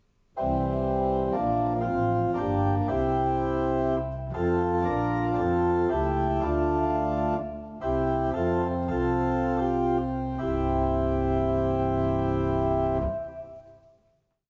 Amen (einfach)
Halleluja.mp3